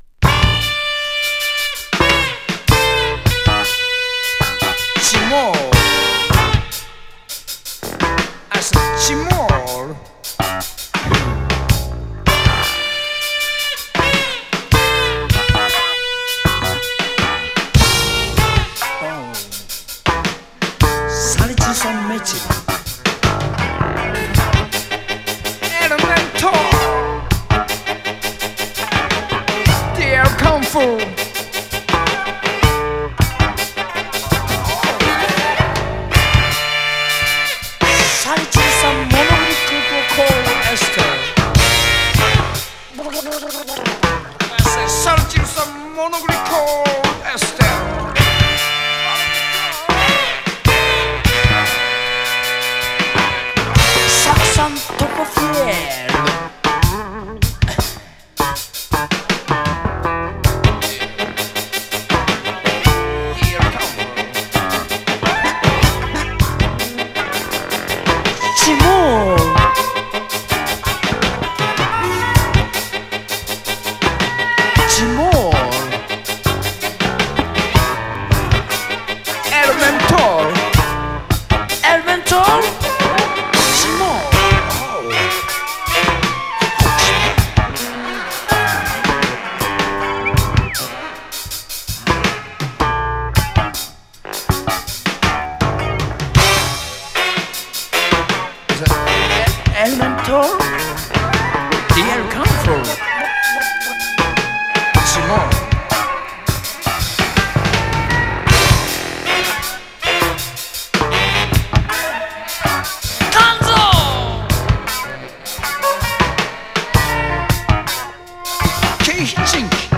ポップでジャズでアバンギャルドな名盤